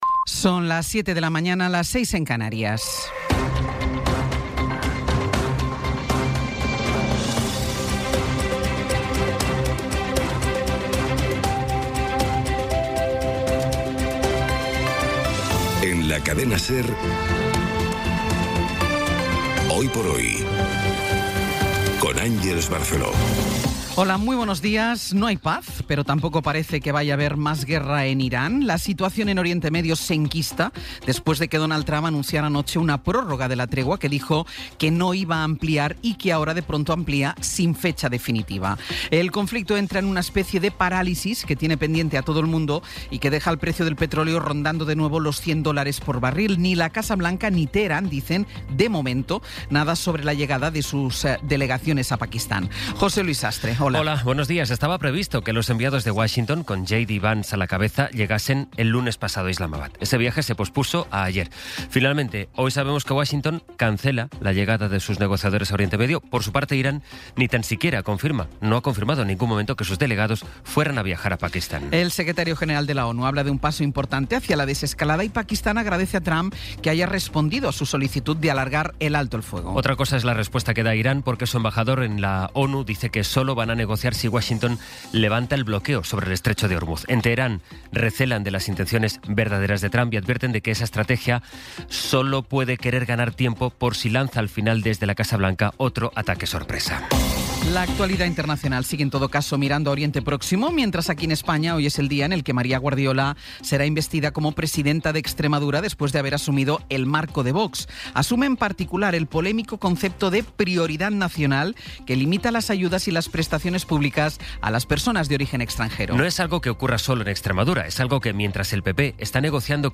Resumen informativo con las noticias más destacadas del 22 de abril de 2026 a las siete de la mañana.